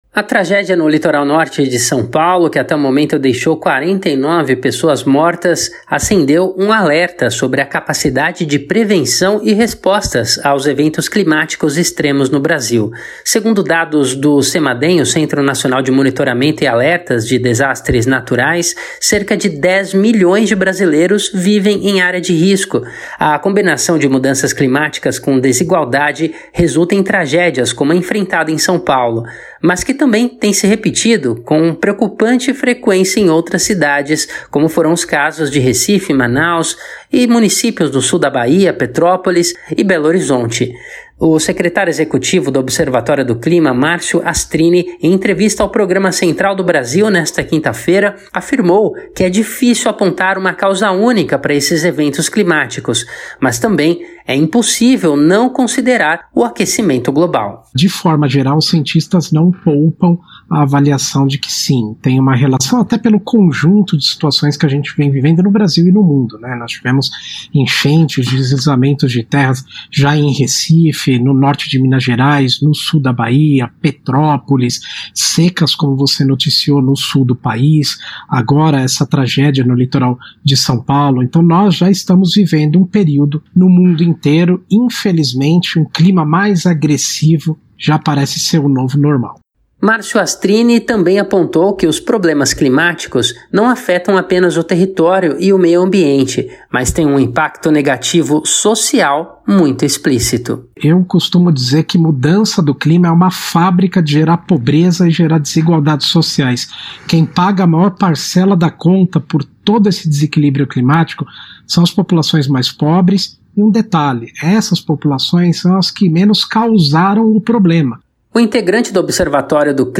em entrevista ao programa Central do Brasil desta quinta-feira(23)